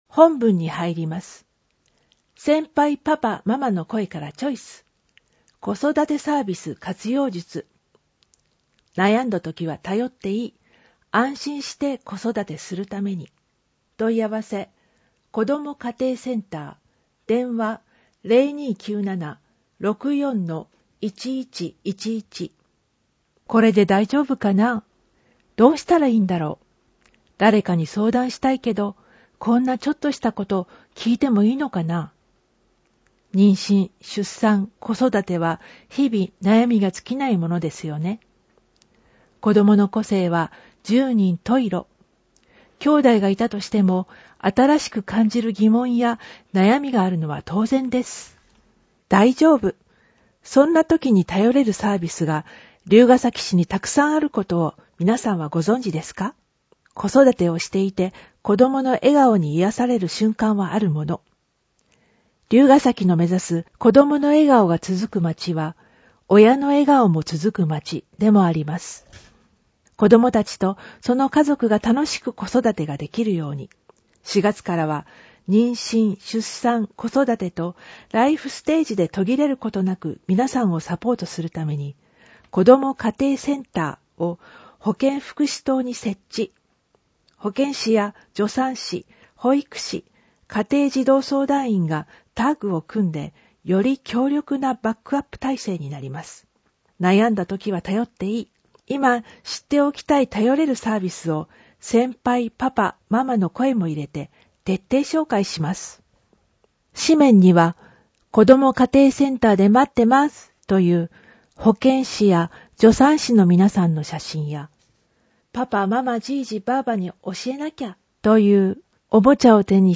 『りゅうほー』の音訳CD・点訳版を配布しています